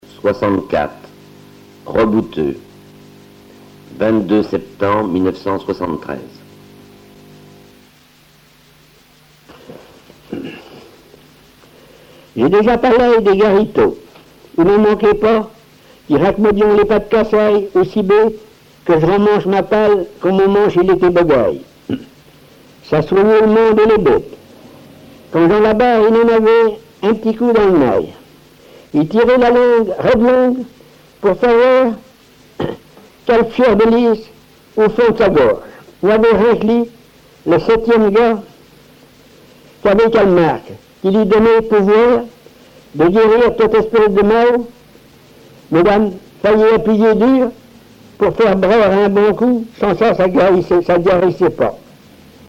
Patois local
Genre récit
Récits en patois